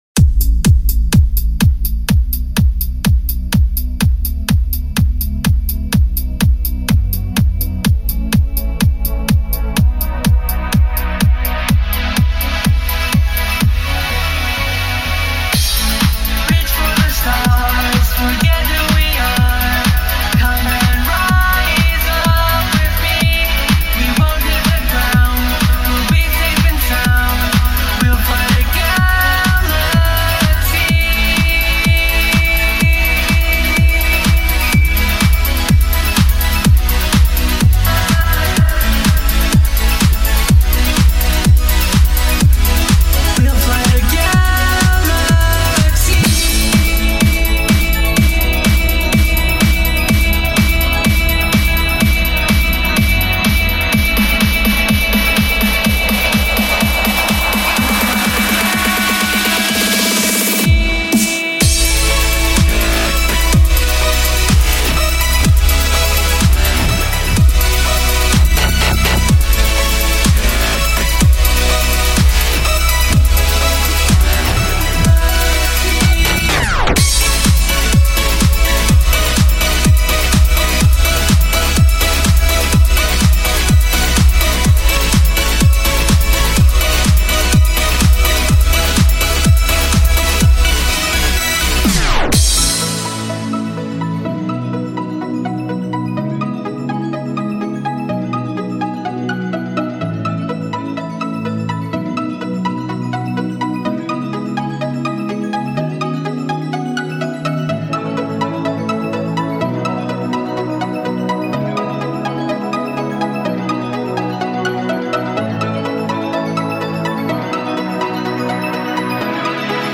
Genre: Dubstep/Electronic BPM: 125BPM